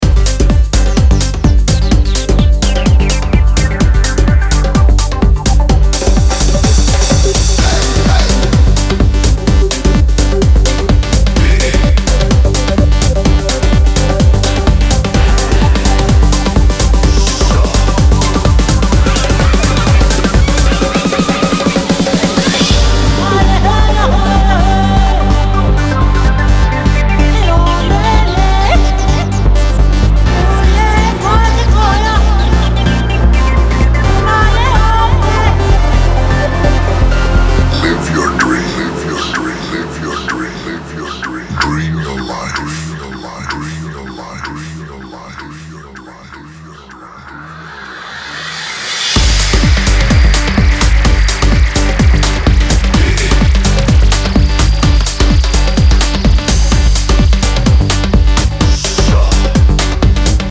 spoken vocals
• Afro House